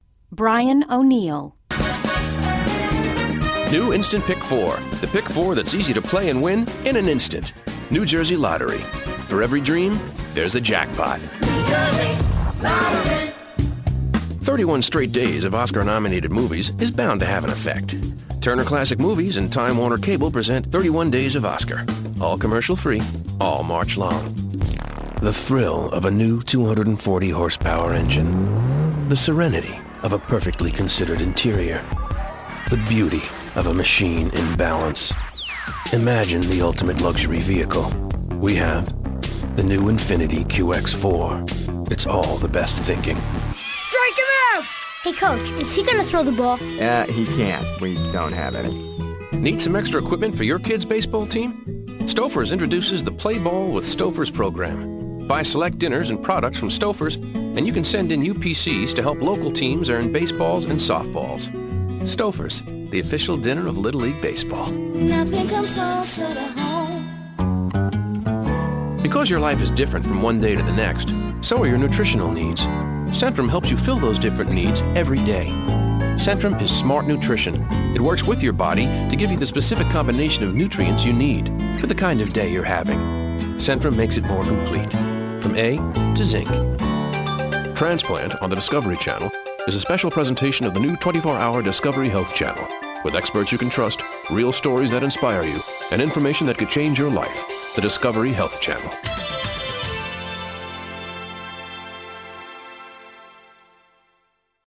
commercial : men